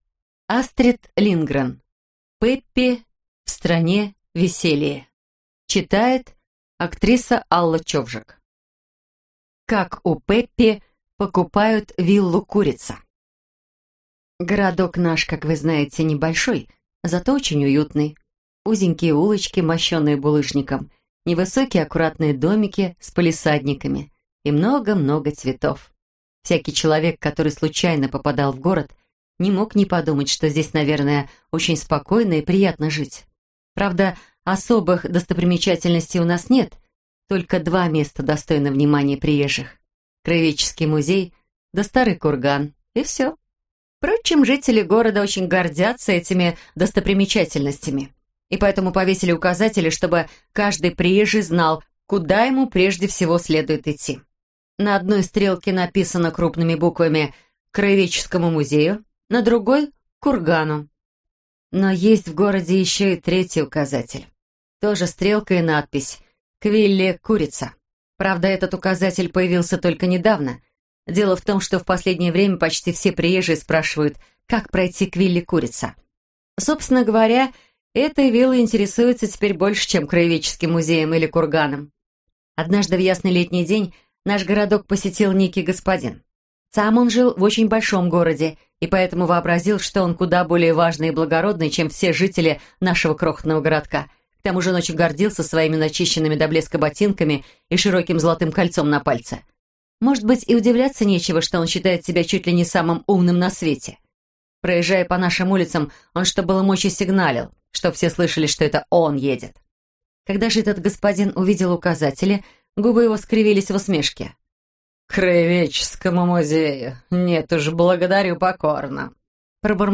Аудиокнига Пеппи Длинныйчулок в стране Веселии - купить, скачать и слушать онлайн | КнигоПоиск